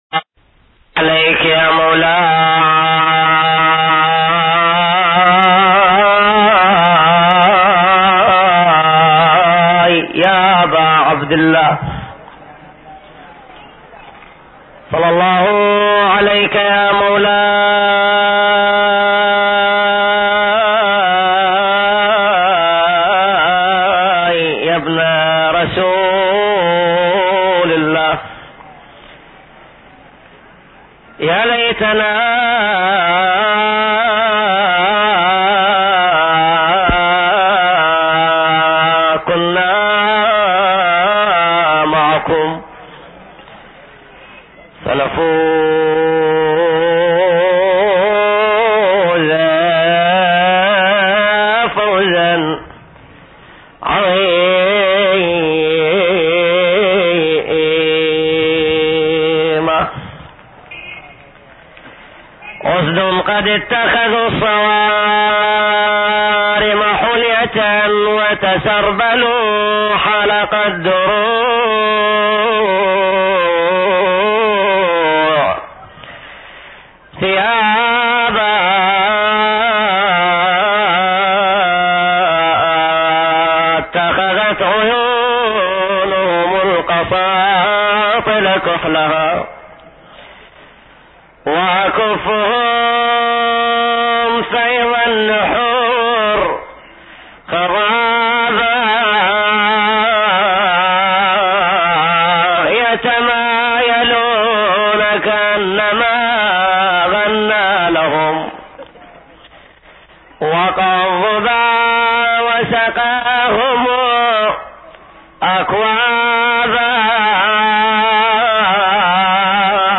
من مجالسه القديمة – في كربلاء – 2 ( دخول السبايا الى الشام )